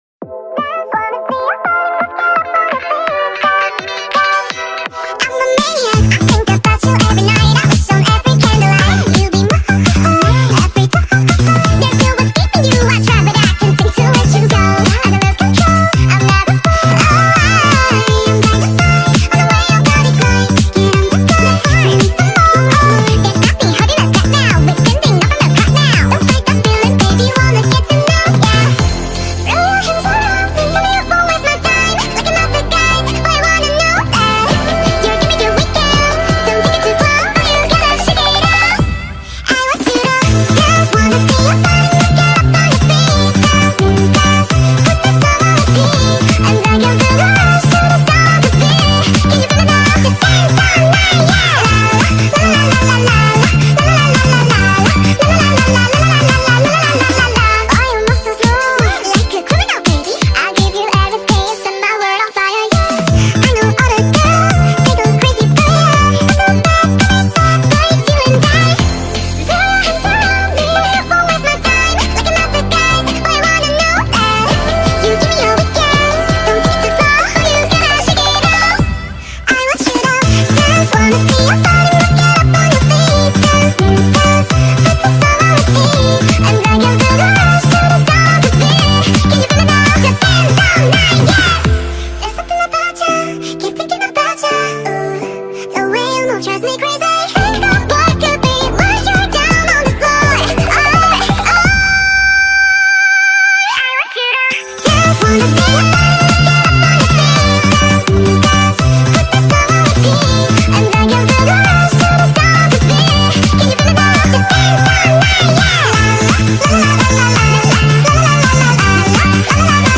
speed up ver. { Kpop speed up song